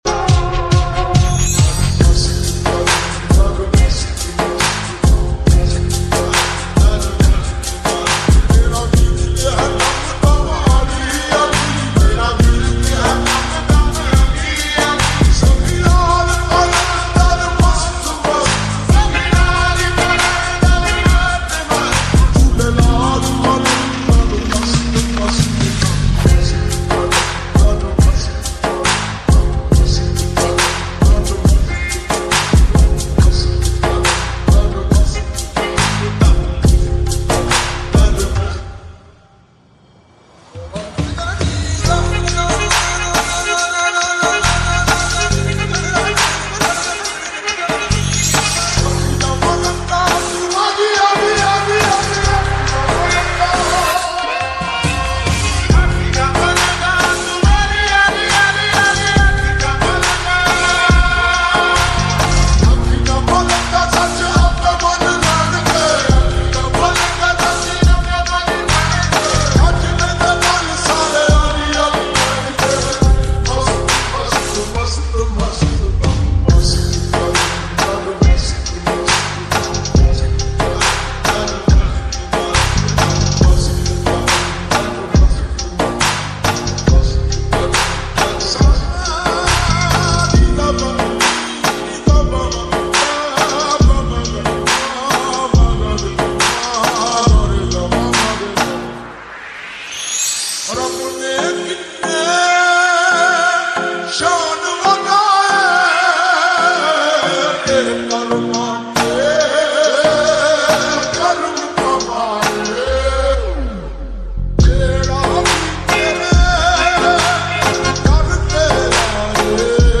QAWALI ❤💗 (SOLVED REVERB